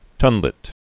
(tŭnlĭt)